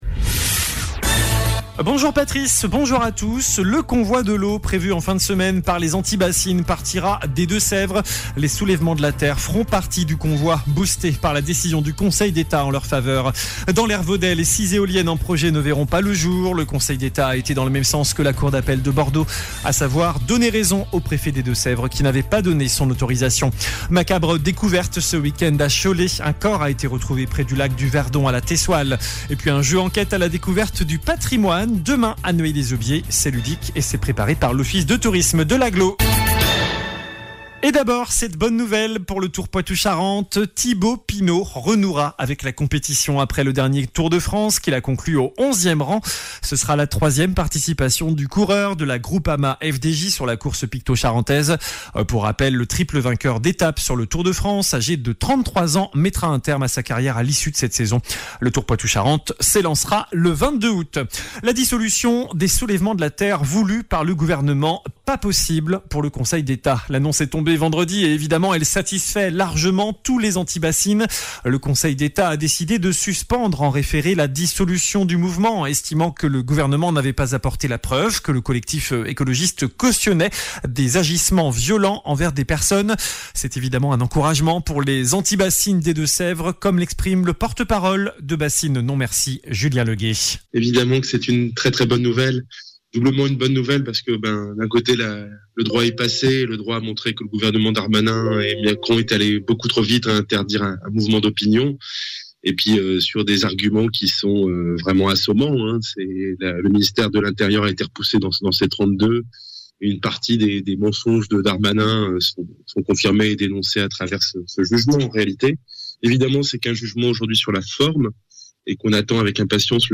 JOURNAL DU MERCREDI 16 AOÛT ( MIDI )